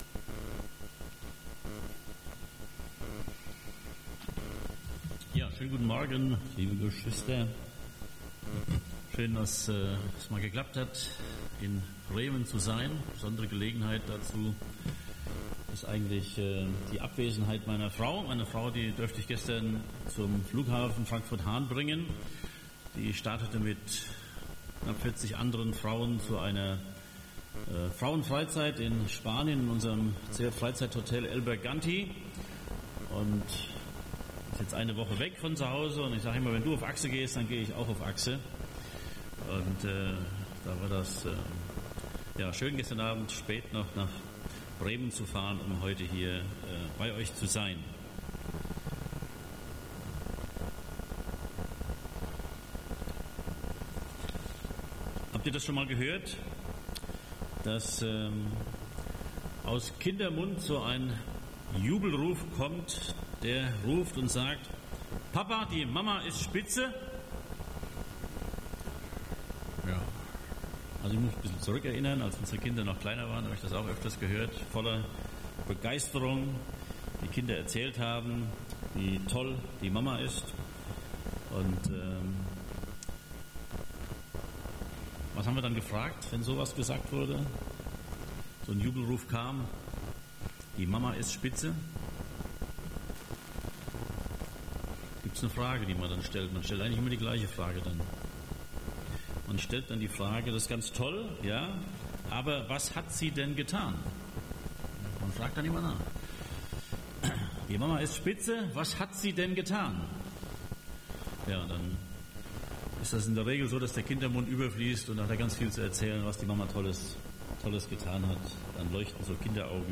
Predigten zum Anhören – Brüdergemeinde-Bremen